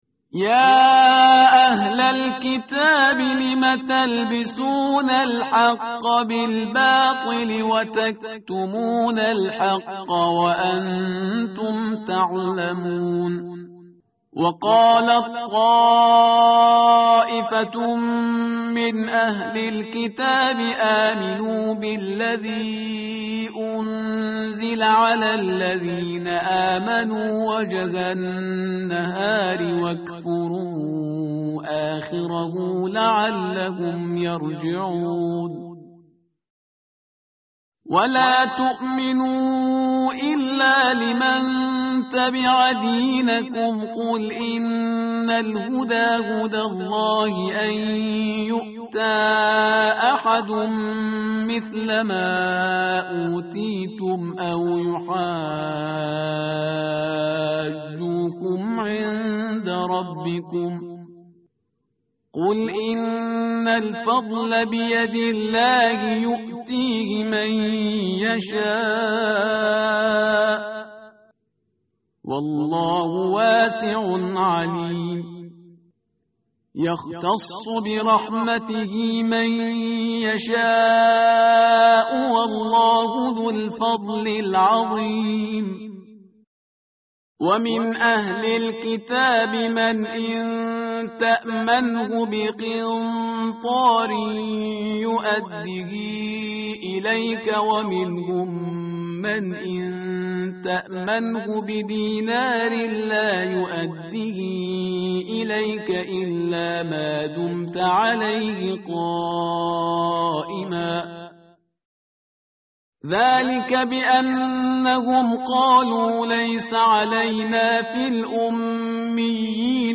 متن قرآن همراه باتلاوت قرآن و ترجمه
tartil_parhizgar_page_059.mp3